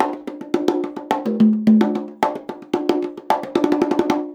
110 CONGA 5.wav